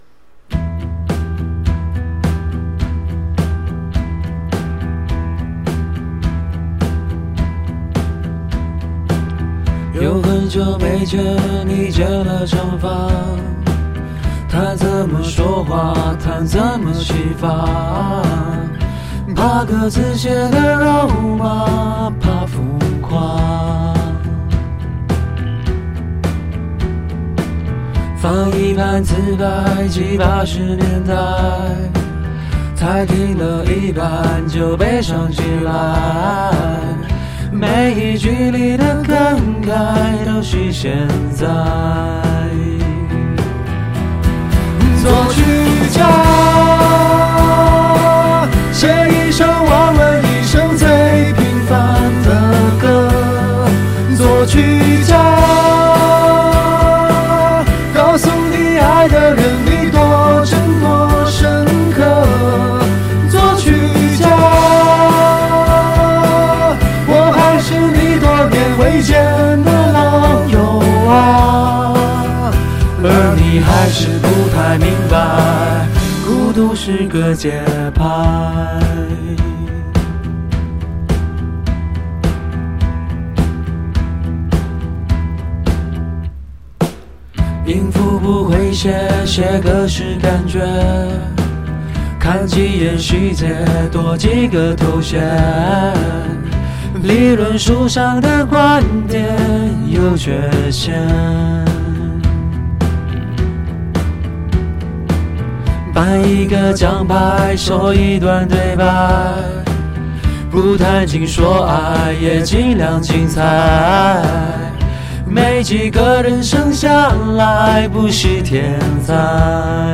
pls: 我找不到伴奏版…所以就只好和原唱合唱了….（被打